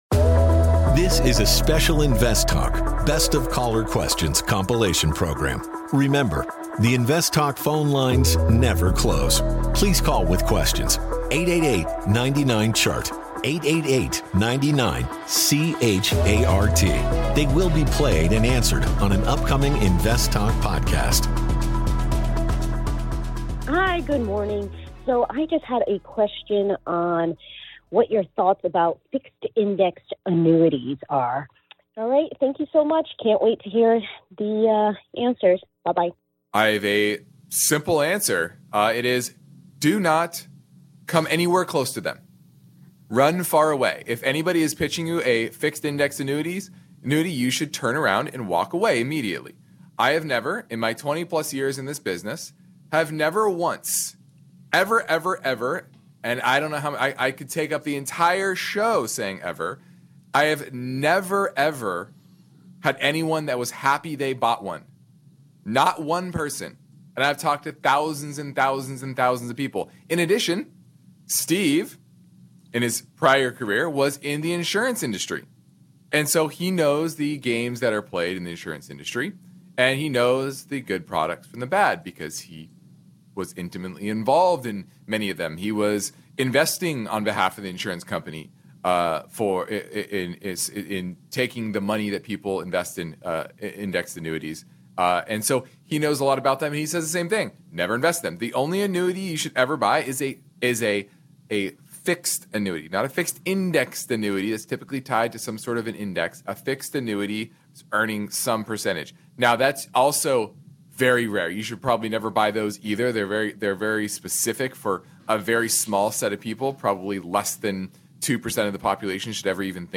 Best of Caller Questions